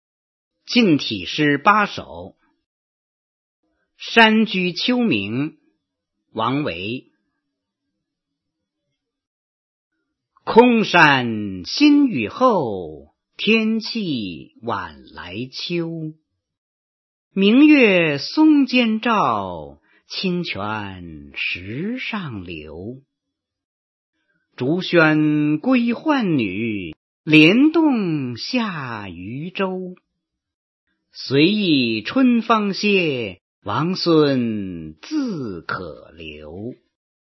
王维《山居秋暝》原文和译文（含赏析、朗读）